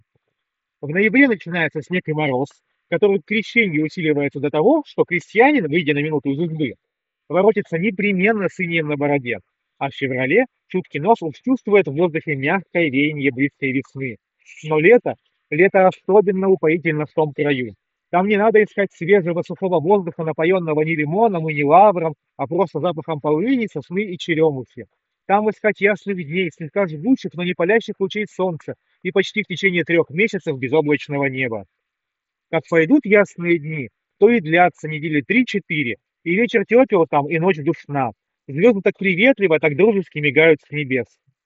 Чтобы оценить ее эффективность, мы вновь запишем два тестовых фрагмента, но на этот раз добавим фоновый шум: включим в расположенной недалеко от места записи акустике звук оживленной улицы и посмотрим, как это отразится на разборчивости речи.
Запись с микрофона гарнитуры (в шумной обстановке)
Работает система крайне эффективно, внешние шумы почти не слышны. Однако достигается это ценой снижения естественности звучания голоса — низкие частоты заметно уводятся назад, из-за чего появляется выраженный «телефонный эффект».